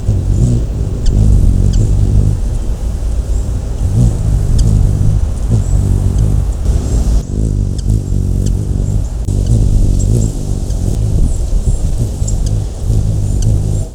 Zumbador de Garganta Roja
Ruby-throated Hummingbird
Archilochus colubris